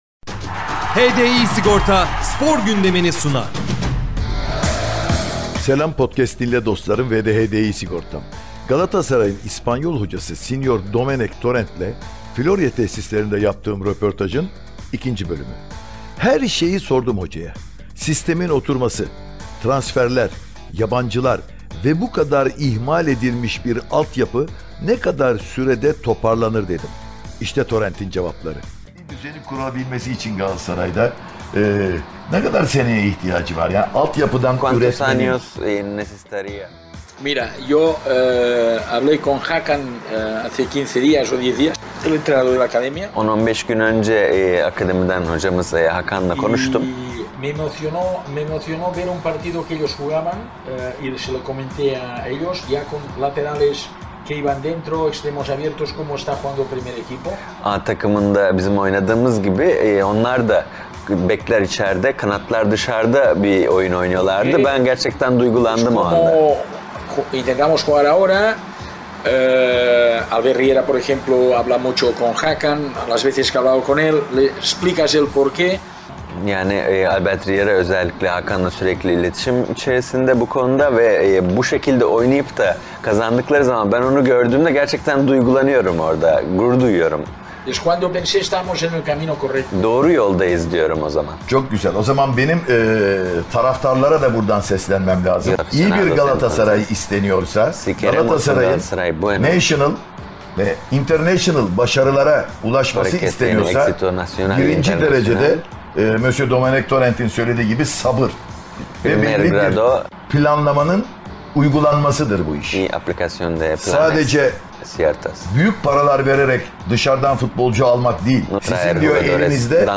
Domenec Torrent röportajı 2.Bölüm ⚽ – Podcast Dİnle